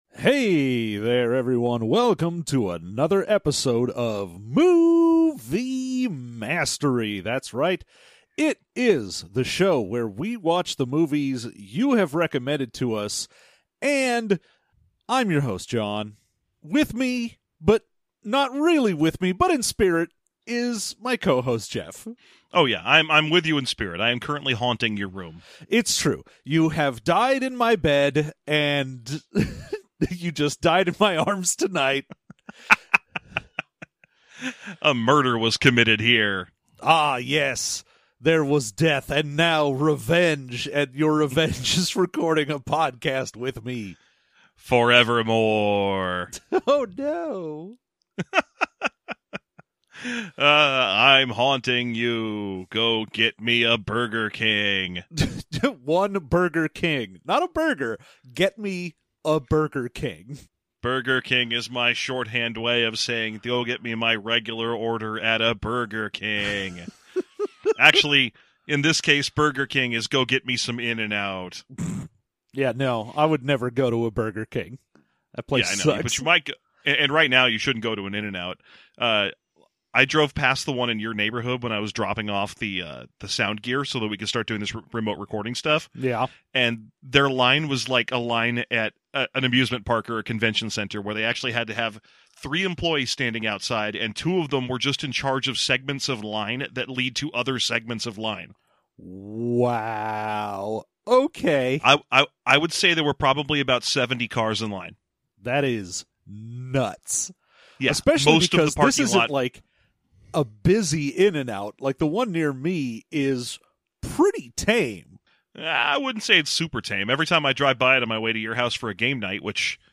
Movie Mastery – Bee Movie (2007) but Every Time We Say Bee the Review Goes Faster
Let’s follow along with his entitled crusade to become a lawyer and sue for all the honey he never worked for anyway, and sort of kind of falls in love with a human? Also every time we say “Bee” the review starts going faster.